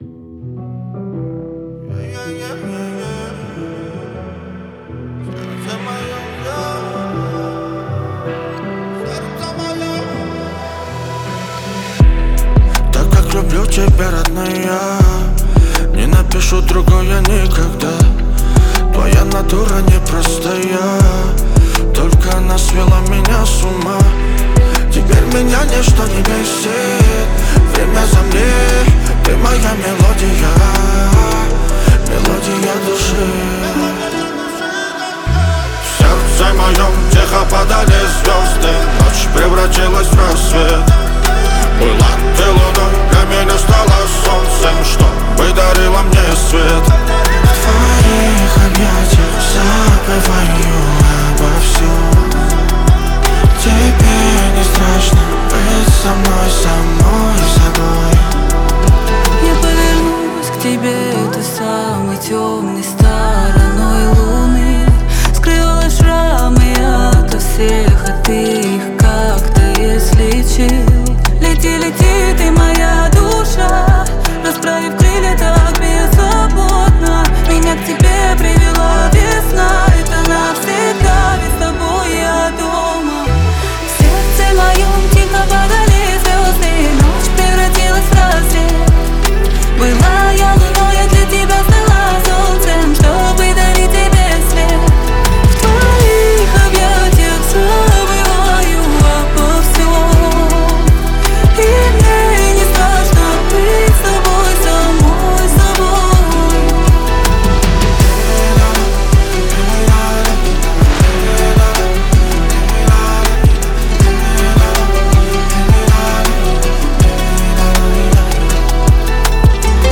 Жанр: Новинки русской музыки